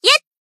BA_V_Serika_Battle_Shout_5.ogg